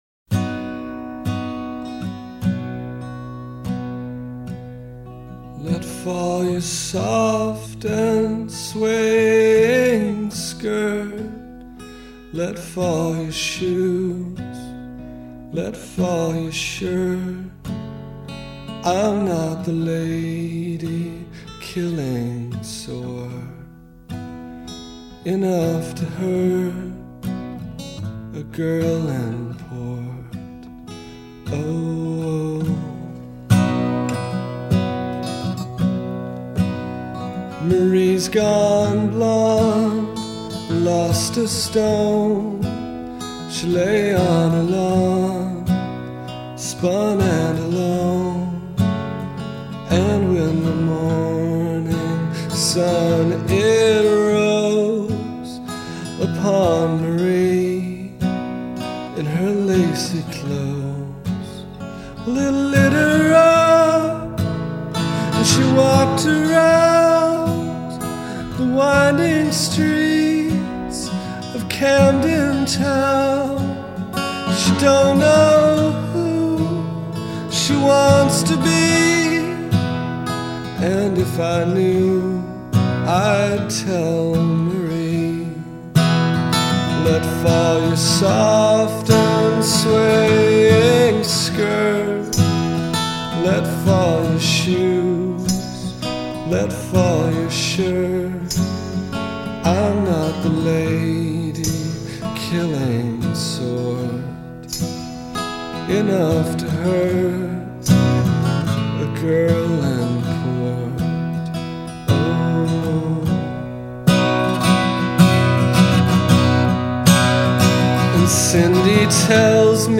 [Demo]